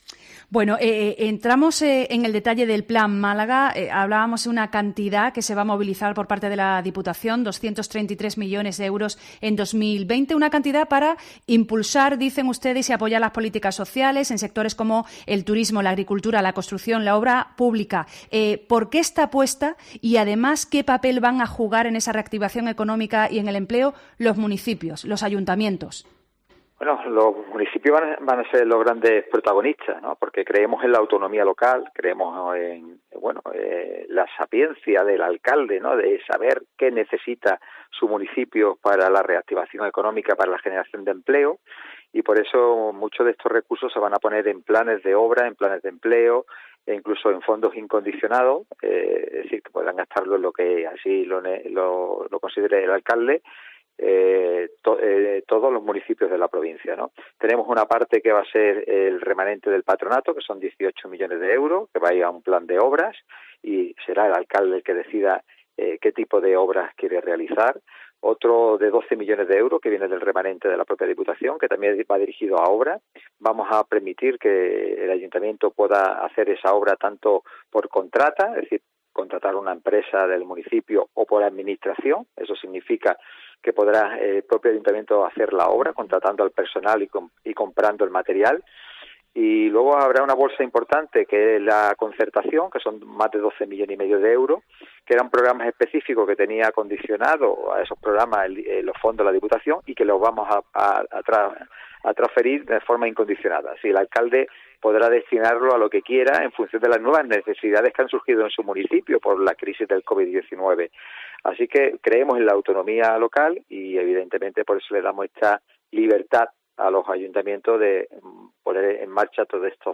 Escucha aquí la entrevista con Francisco Salado, presidente de Diputación.